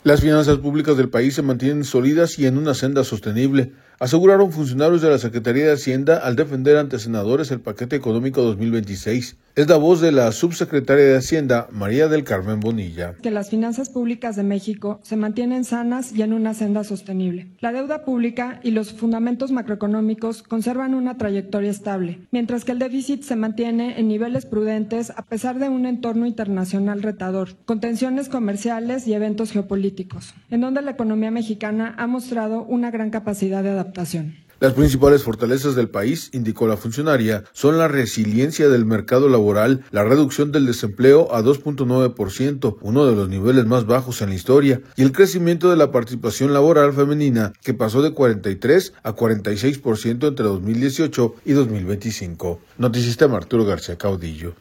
Las finanzas públicas del país se mantienen sólidas y en una senda sostenible, aseguraron funcionarios de la Secretaría de Hacienda al defender ante senadores el Paquete Económico 2026. Es la voz de la subsecretaria de Hacienda, María del Carmen Bonilla.